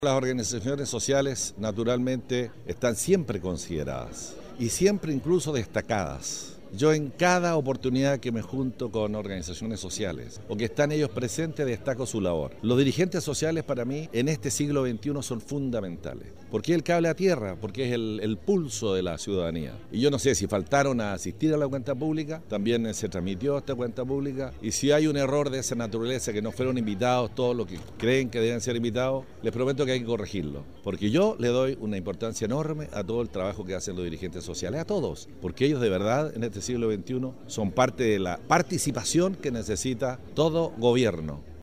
La cuenta pública participativa, contó con la presencia de las distintas autoridades políticas y de gobierno, además de representantes de las Fuerzas Armadas y de Orden, donde el intendente Harry Jurgensen, respondió a las preguntas manifestadas por ciudadanos de esta región.